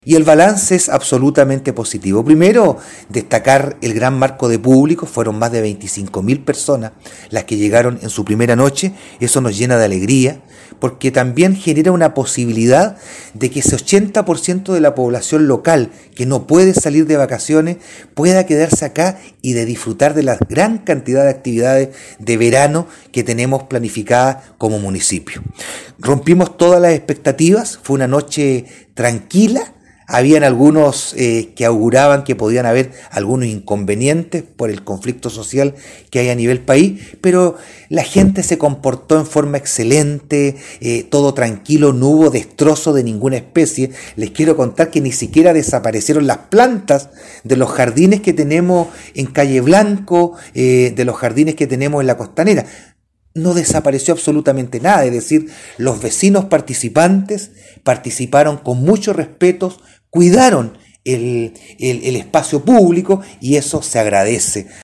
ALCALDE-VERA-BALANCE-FESTIVAL-1.mp3